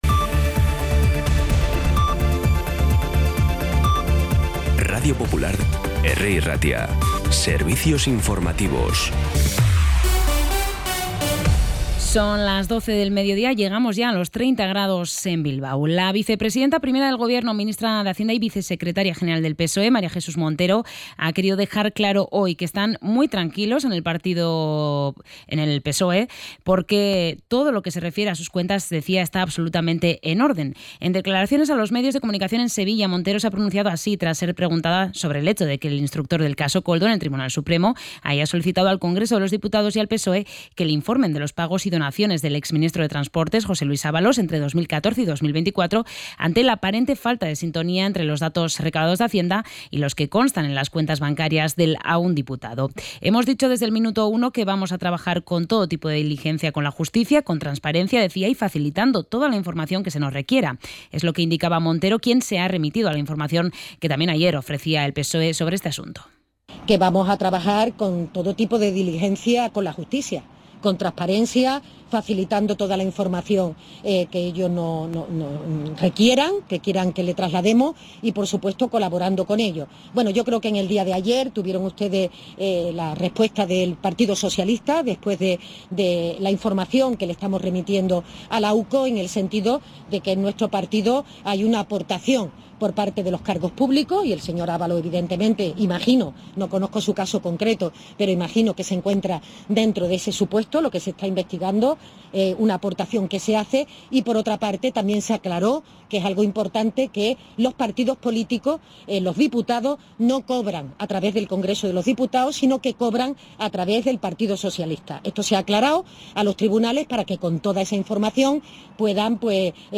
Boletín de noticias de Bizkaia
Los titulares actualizados con las voces del día.